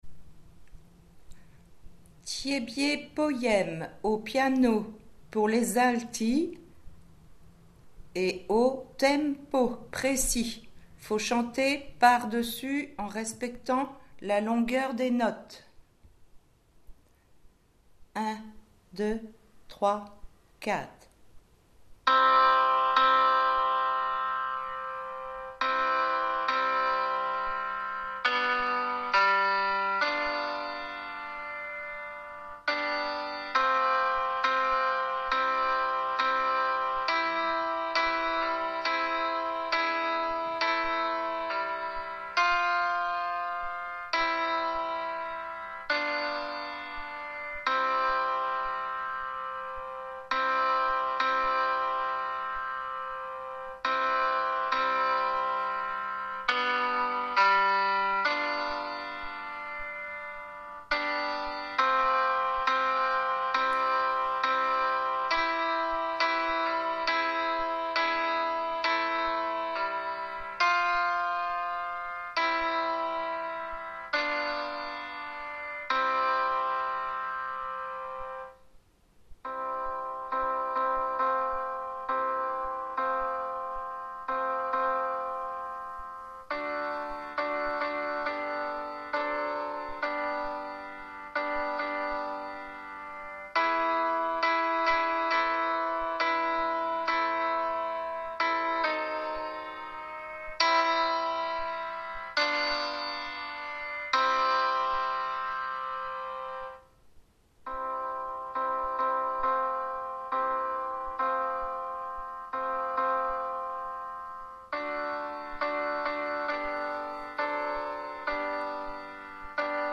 Tiébié piano Alti
tiebie-piano-alti.mp3